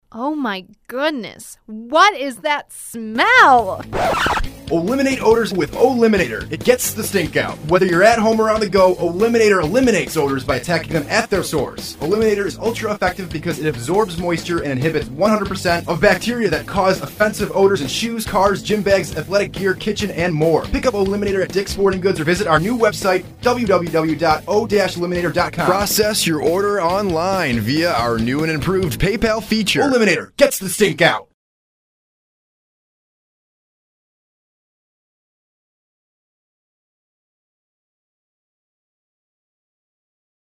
New Radio Ad